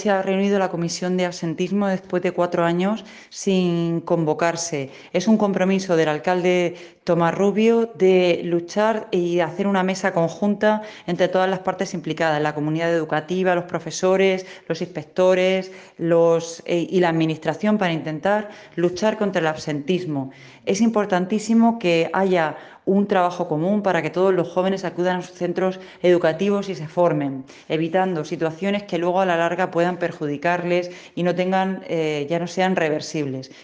Statements by María Turpín, Councillor for Education.